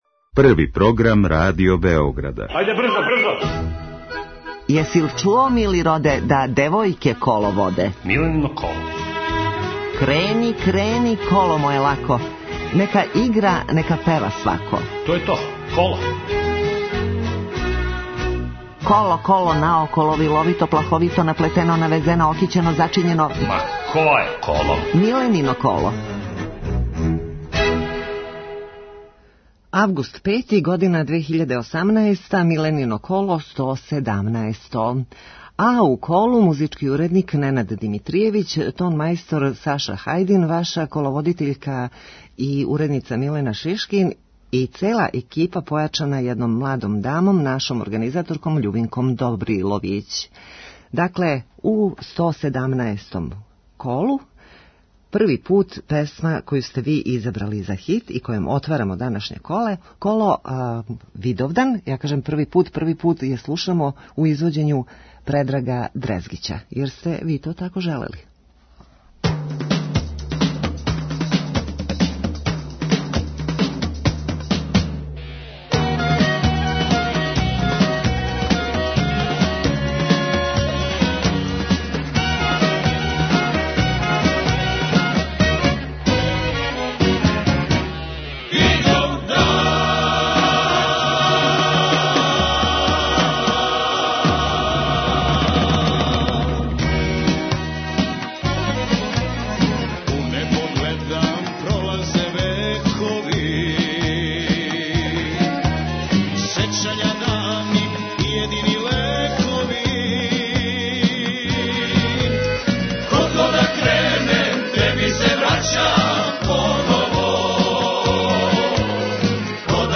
Емисија се емитује недељом од 11.05 до 12.00 о народној музици, искључиво са гостима који су на било који начин везани за народну музику, било изворну, било новокомпоновану (певачи, композитори, текстописци, музичари...). Разговор са гостом забавног карактера - анегдоте из професионалног живота, најдража песма, највећи успех, хоби и томе слично.